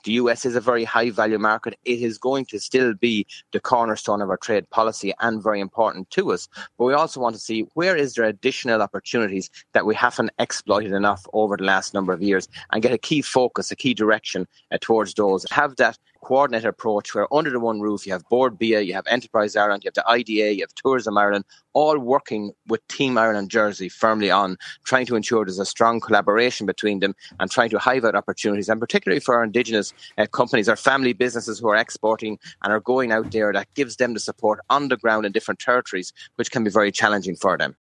Enterprise Minister Peter Burke, says it’s a whole of Government approach…………….